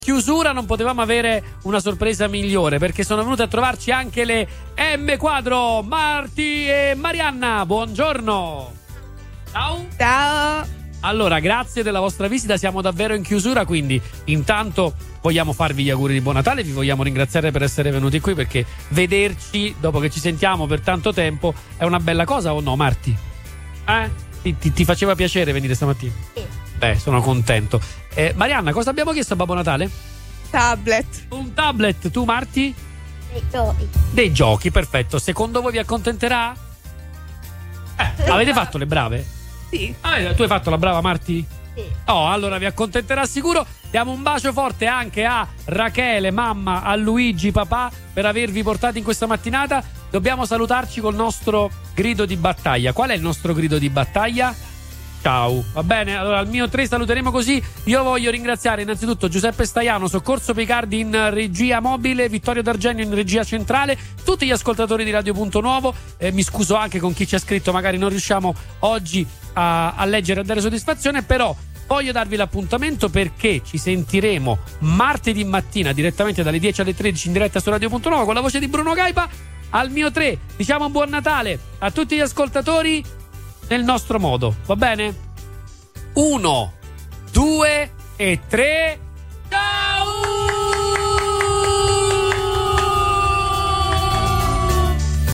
L’atmosfera natalizia è stata resa ancora più speciale dalla diretta radiofonica che ha accompagnato gli aperitivi della vigilia, trasmettendo gioia e festa .